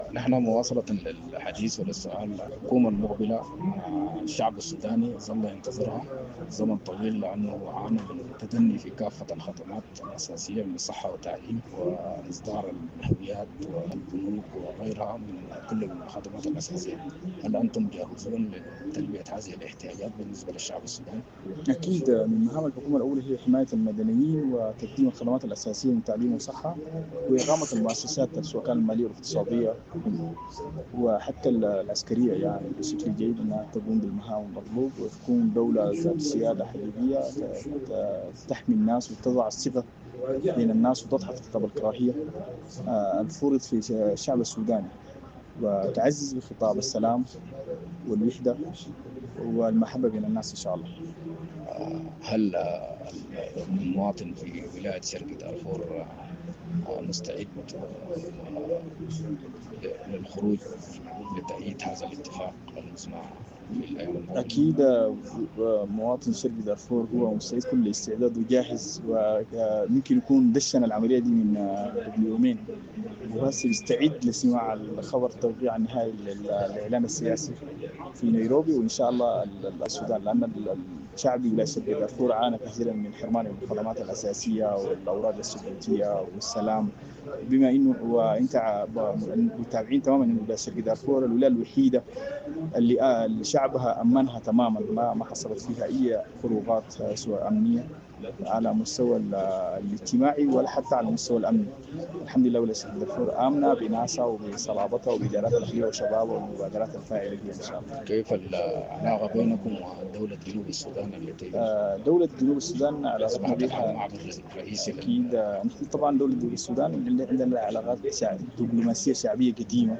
وطمئن رئيس الإدارة المدنية في حديث “للسودانية نيوز” فيما يخص مهام الحكومة المقبلة، وهي تقديم الخدمات الأساسية للمواطنين من صحة وتعليم ، وإقامة المؤسسات الاقتصادية والمالية، والعدلية والشرطية، حتي تقوم بمهامها المطلوبة، خاصة حماية المواطن ومنع خطاب الكراهية، والعنصرية، وتعزز من خطاب السلام، والوحدة.